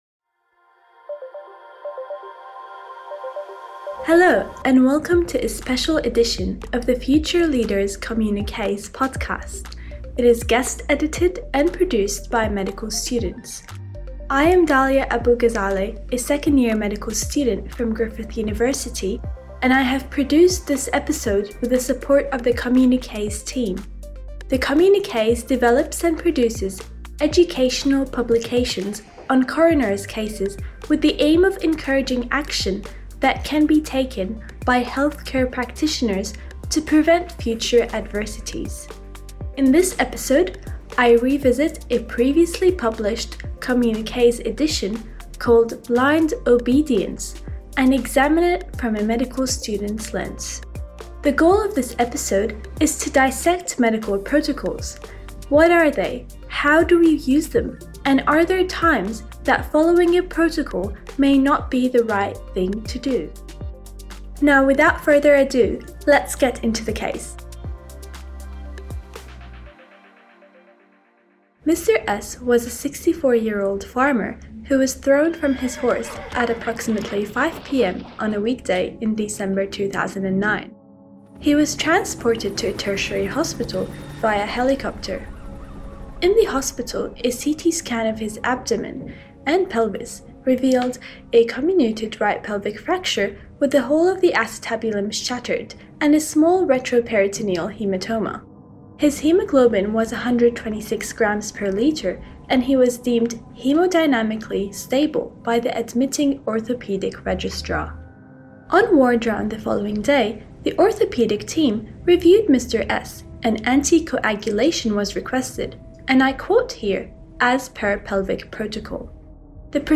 Vox pop